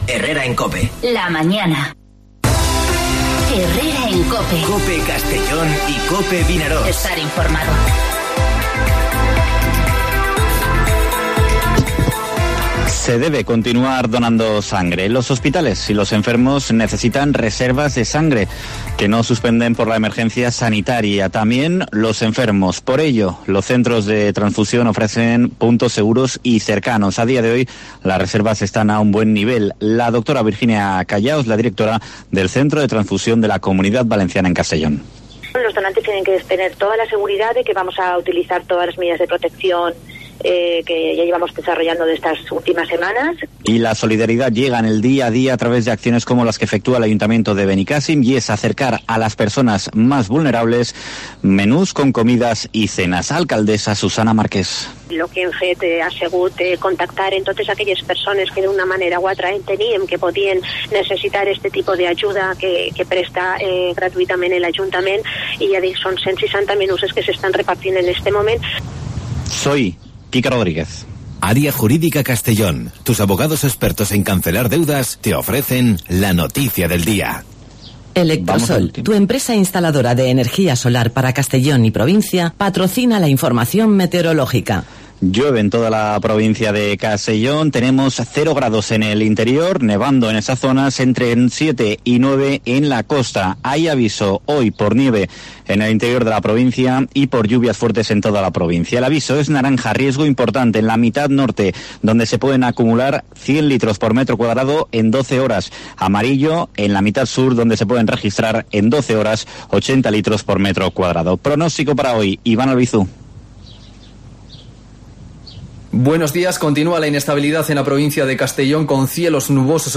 Informativo Herrera en COPE Castellón (31/03/2020)